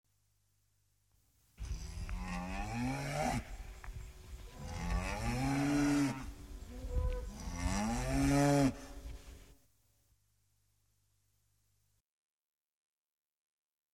Pianeta Gratis - Audio - Animali
bovini_cow_bull07.mp3